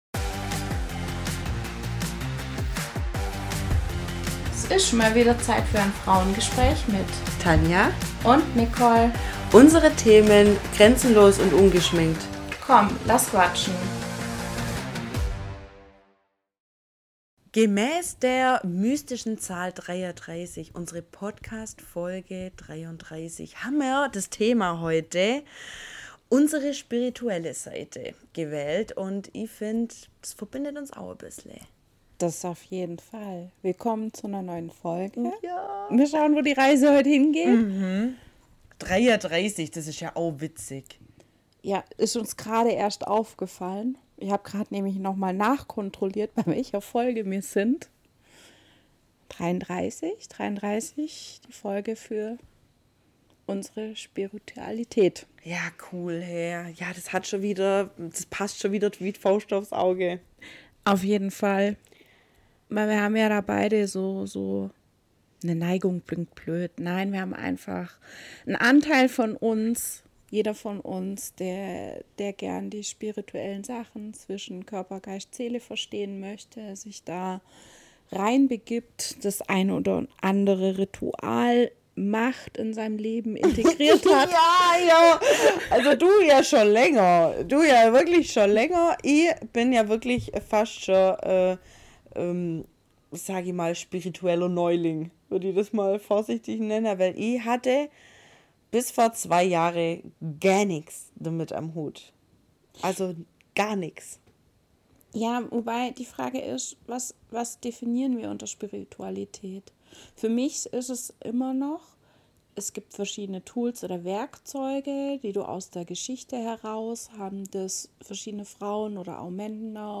#033 Unsere spirituelle Seite ~ Frauengespräche │ grenzenlos & ungeschminkt Podcast